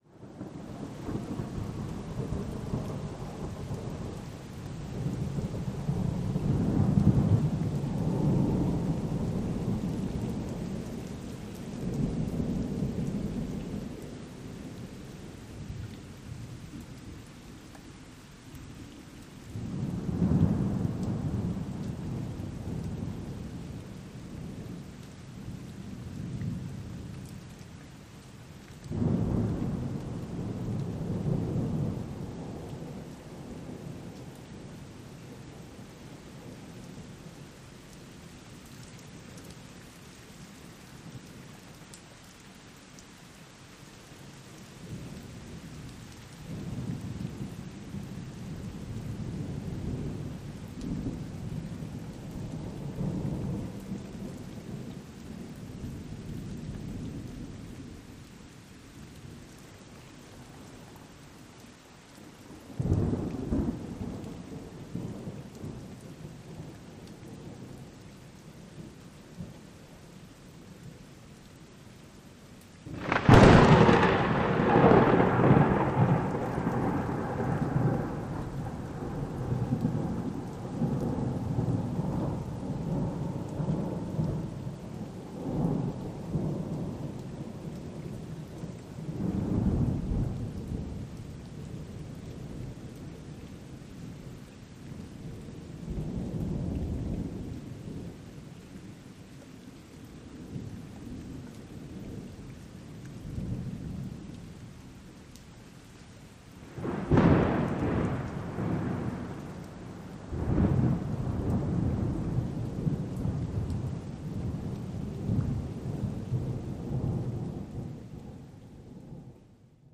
دانلود آهنگ رعد و برق به همراه باران بهاری از افکت صوتی طبیعت و محیط
دانلود صدای رعد و برق به همراه باران بهاری از ساعد نیوز با لینک مستقیم و کیفیت بالا
جلوه های صوتی